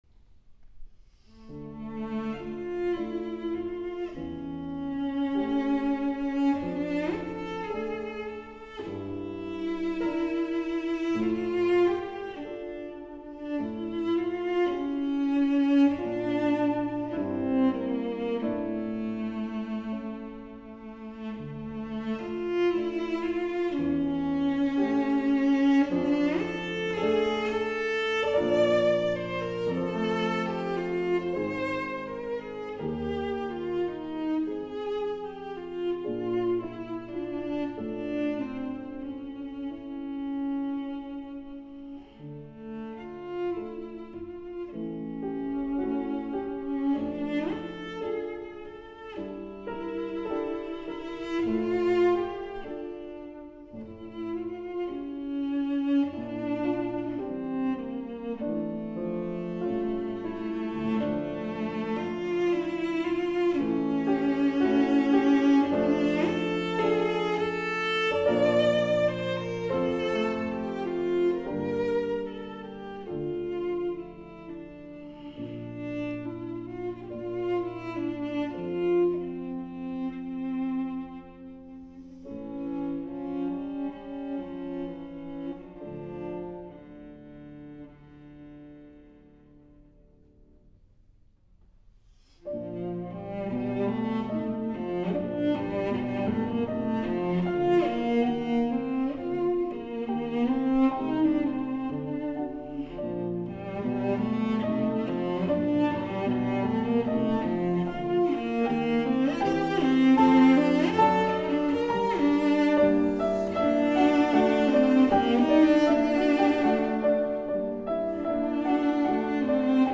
Wigmore live
cello
piano